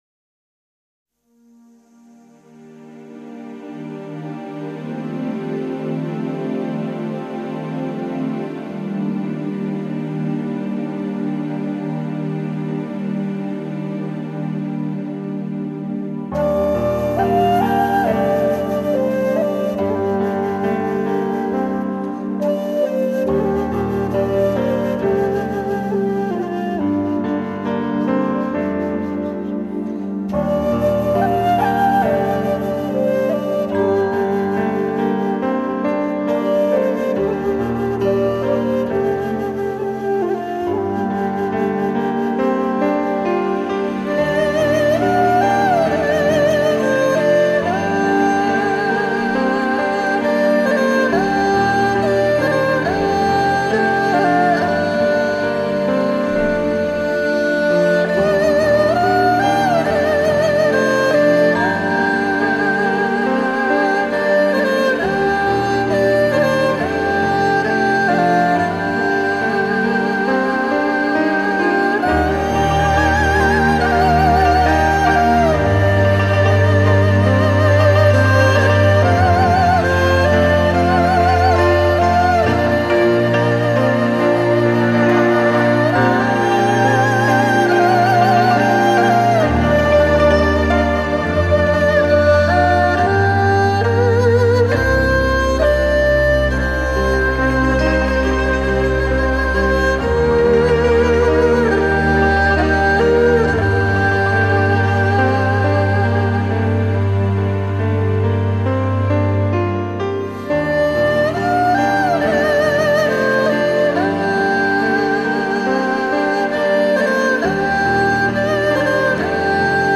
和月，东方神秘声音和西洋音乐的融合，构筑全新的音乐理念。